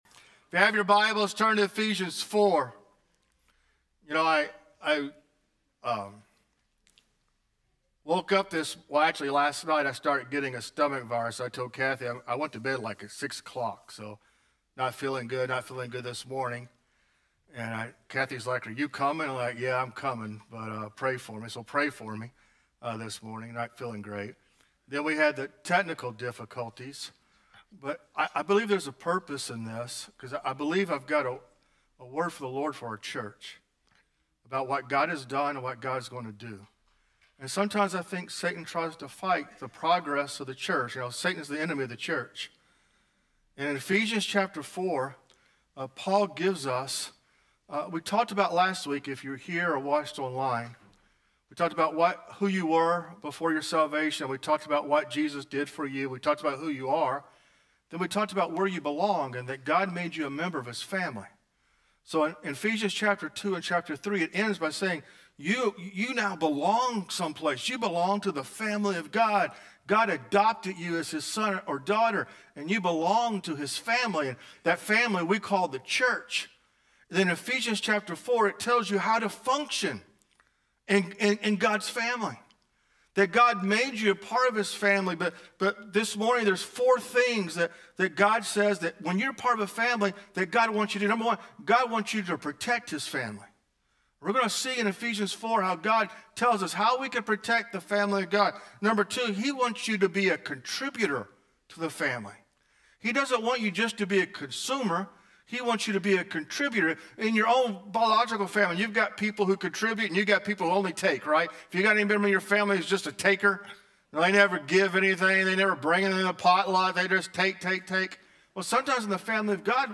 Sermons | First Assembly of God